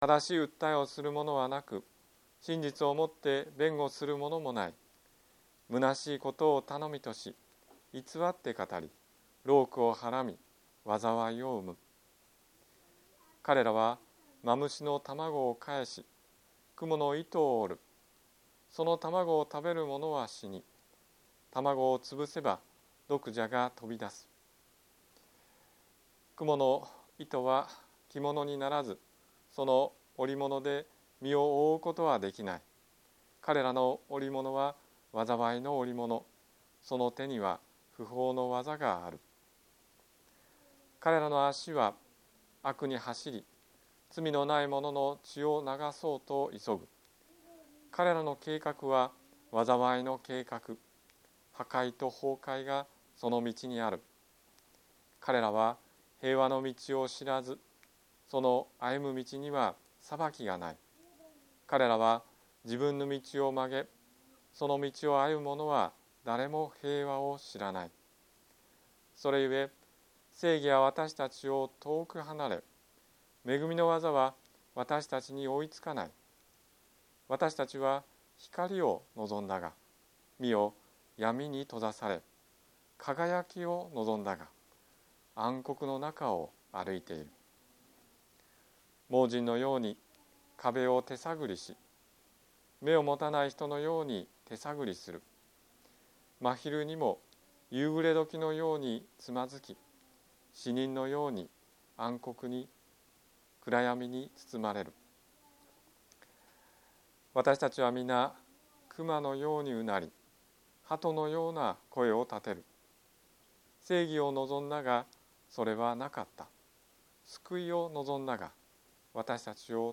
説教アーカイブ。
日曜 朝の礼拝